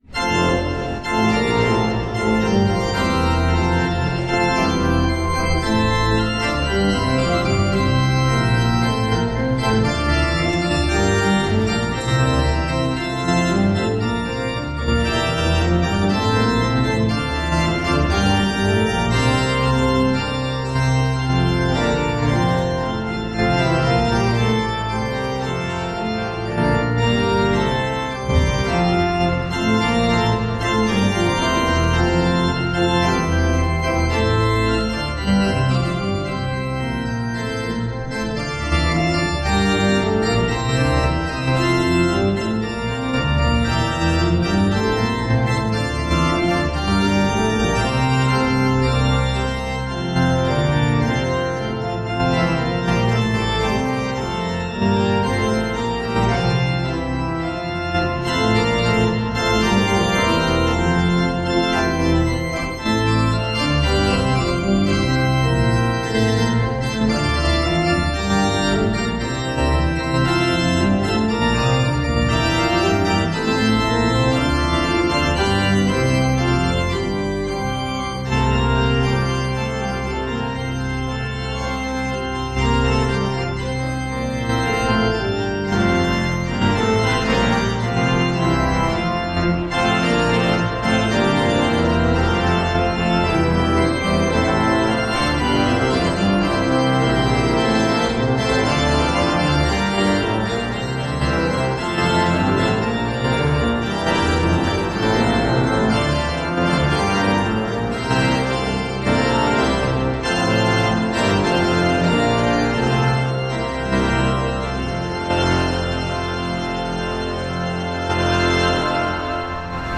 Hear the Bible Study from St. Paul's Lutheran Church in Des Peres, MO, from March 29, 2026.